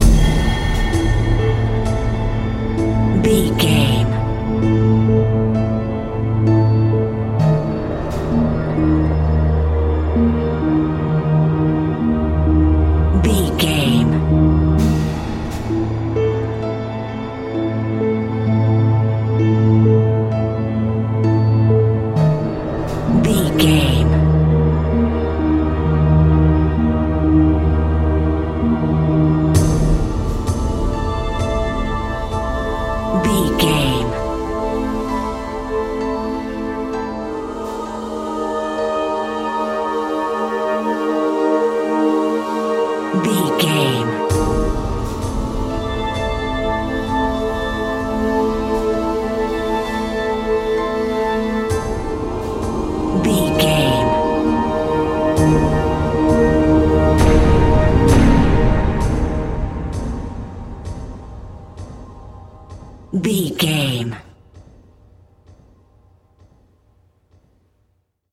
Aeolian/Minor
synthesiser
strings
percussion
ominous
dark
suspense
haunting
creepy
spooky